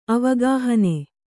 ♪ avagāhane